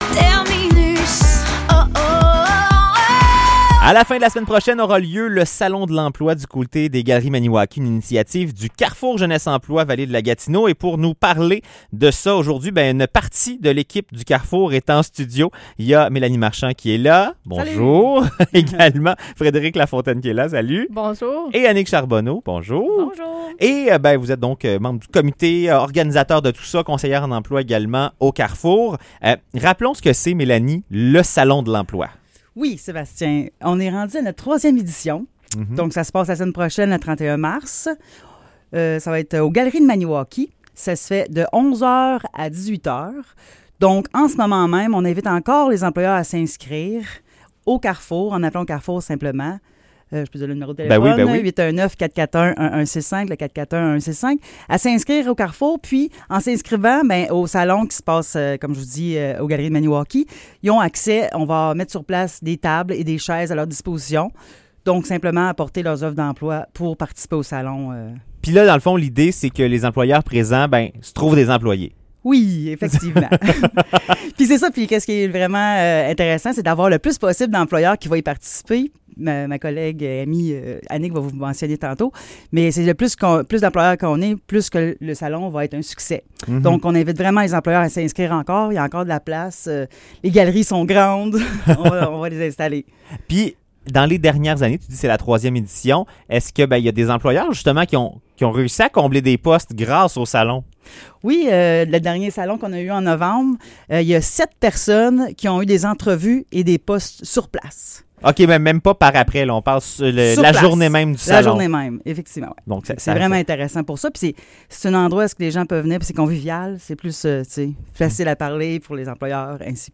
Entrevue sur le Salon de l'emploi